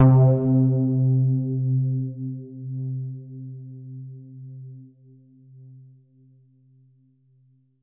SOUND  C2.wav